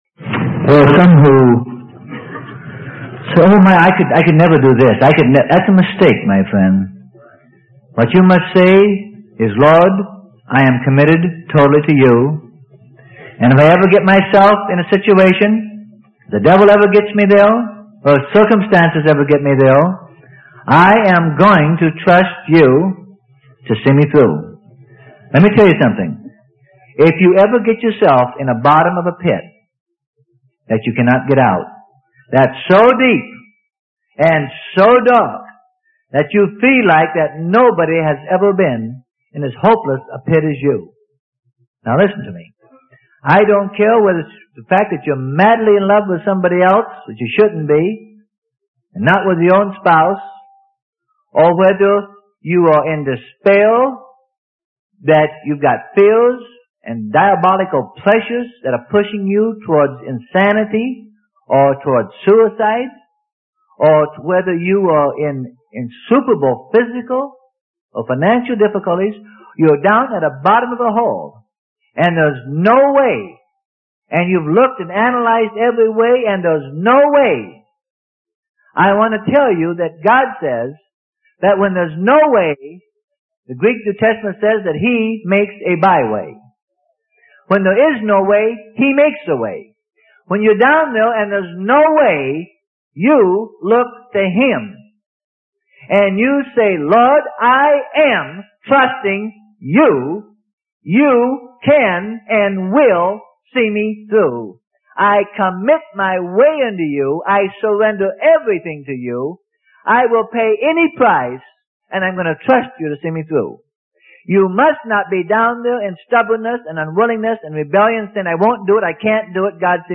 Sermon: Through Death With Him - Part 11 - Freely Given Online Library